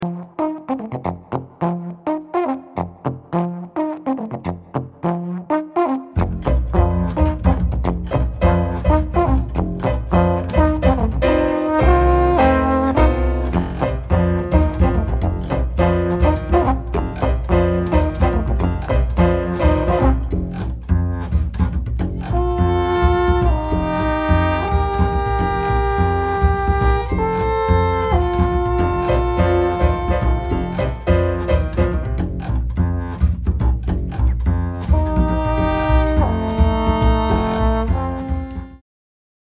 the music was actually created on a farm in Dinhard.
piano, melodica, mellotron
Russian horn, alphorn
bass